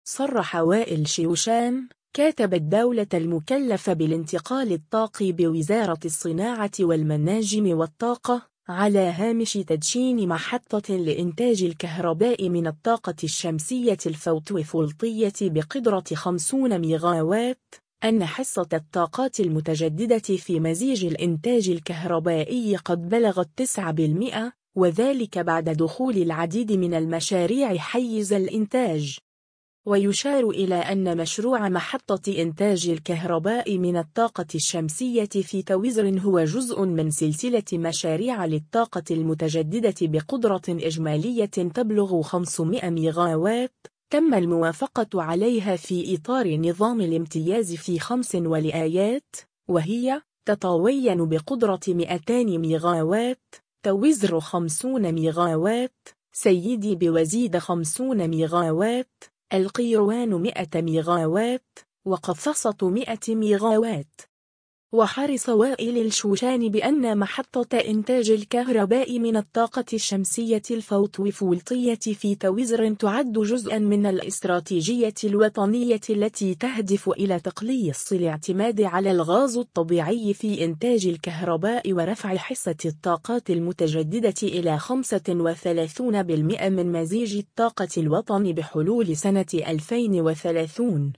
صرح وائل شوشان، كاتب الدولة المكلف بالانتقال الطاقي بوزارة الصناعة والمناجم والطاقة، على هامش تدشين محطة لإنتاج الكهرباء من الطاقة الشمسية الفوتوفولطية بقدرة 50 ميغاوات، أن حصة الطاقات المتجددة في مزيج الإنتاج الكهربائي قد بلغت 9%، وذلك بعد دخول العديد من المشاريع حيز الإنتاج.